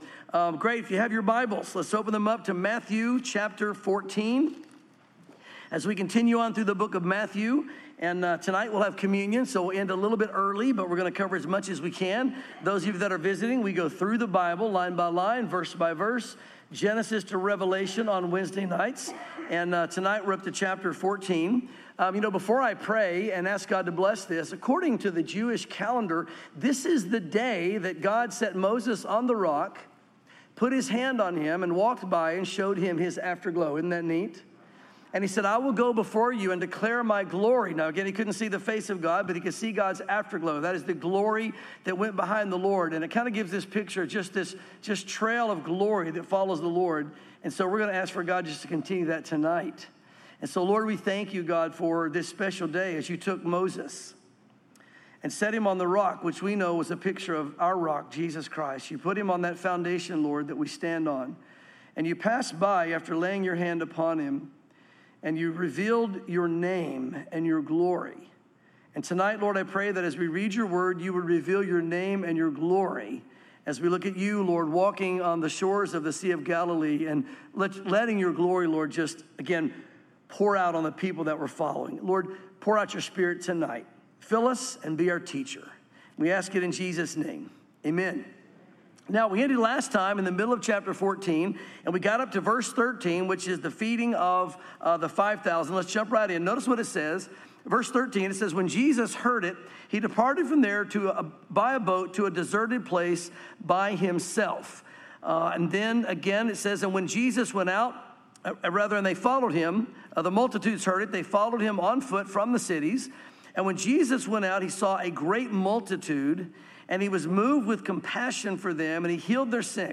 sermons Matthew 14:13 - Chapter 15